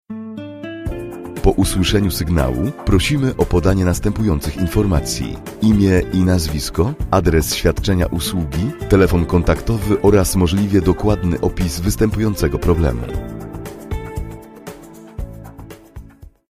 Male 30-50 lat
Nagranie lektorskie